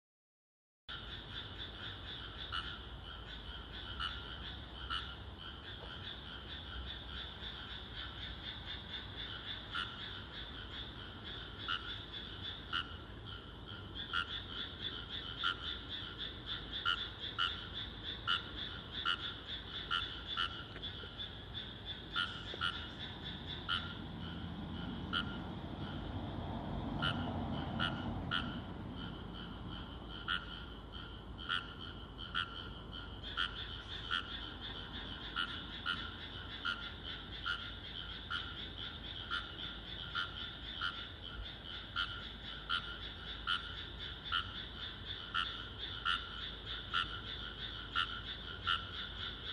カエルの声を聴いてみよう